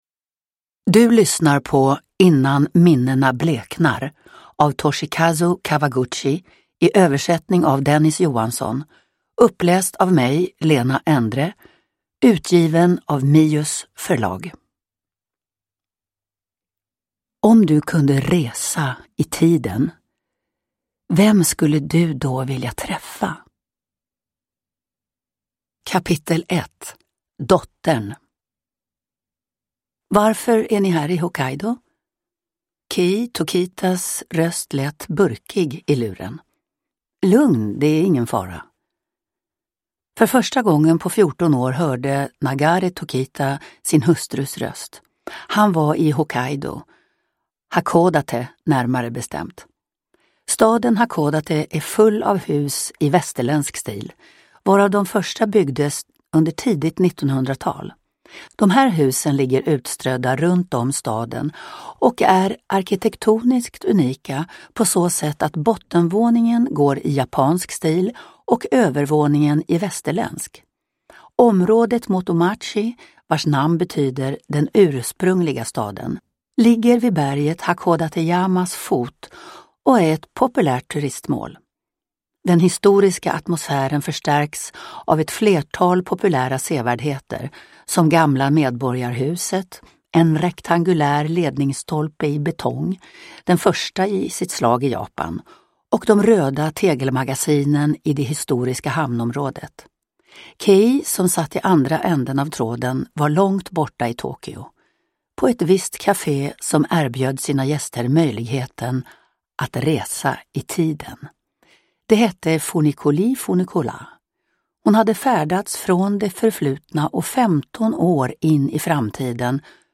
Innan minnena bleknar – Ljudbok
Uppläsare: Lena Endre